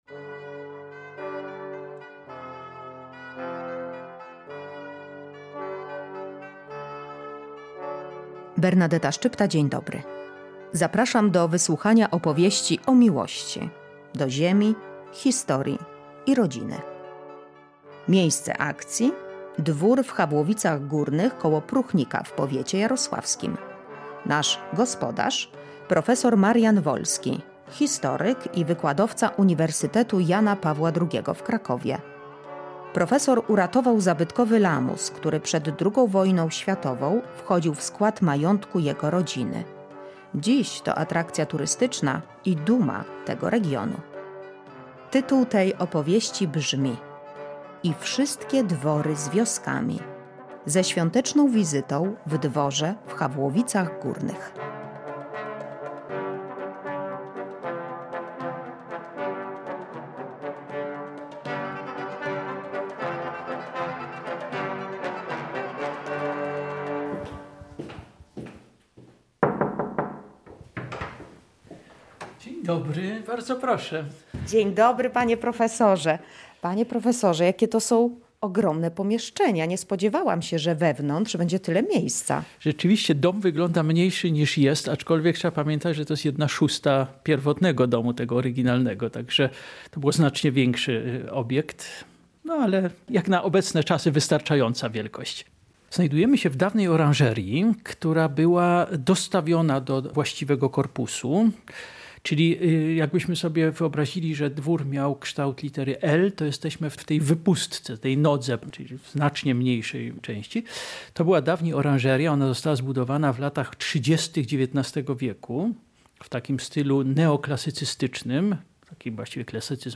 Zapraszamy do wysłuchania opowieści o miłości do ziemi, historii i rodziny. Miejsce akcji: dwór w Hawłowicach Górnych koło Pruchnika w powiecie jarosławskich.